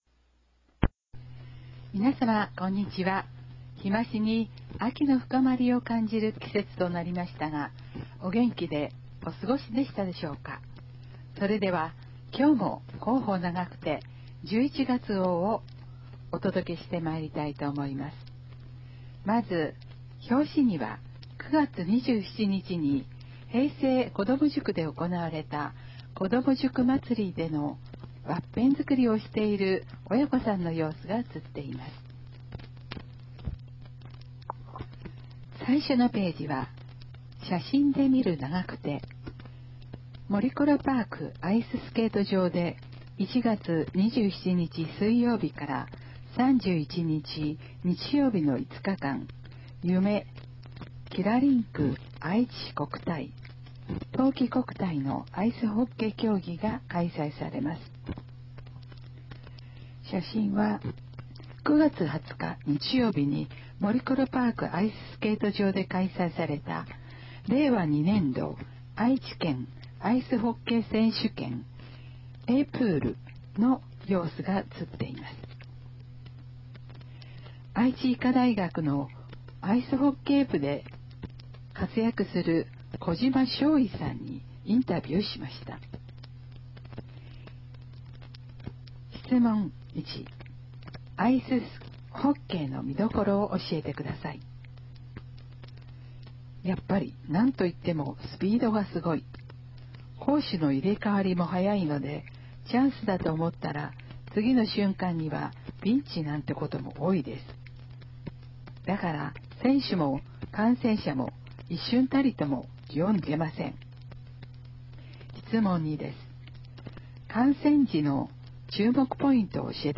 平成29年8月号から、ボランティア団体「愛eyeクラブ」の皆さんの協力により、広報ながくてを概要版として音声化して、ホームページ上で掲載しています。
音声ファイルは、カセットテープに吹き込んだものをMP3ファイルに変換したものです。そのため、多少の雑音が入っています。